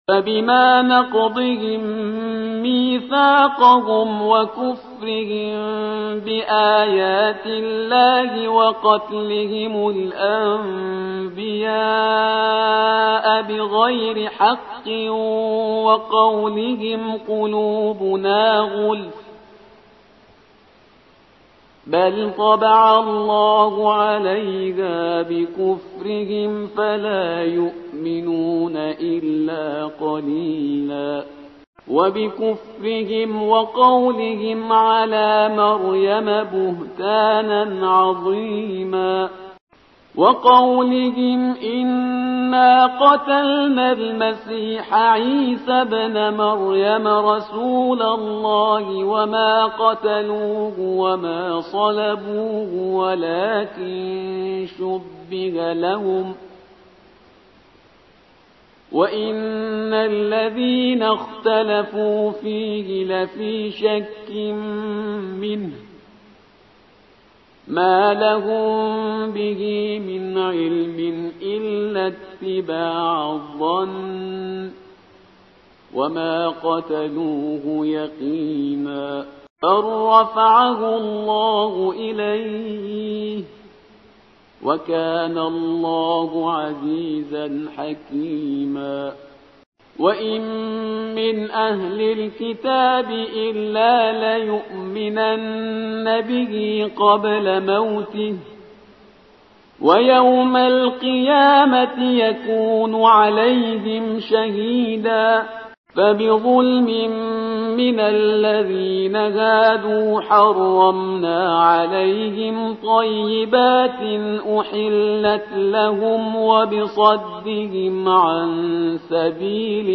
ترتیل سوره(نساء)